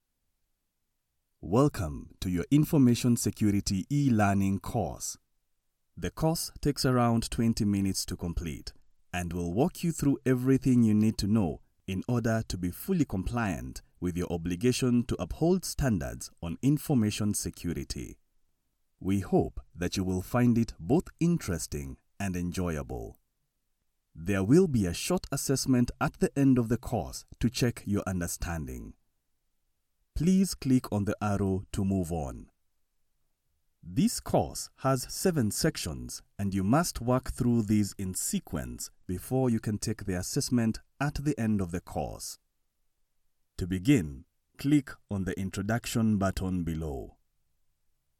Englisch (afrikanisch)
E-Learning
TiefNiedrig
ReifenWarmLustigAutorisierendEmotional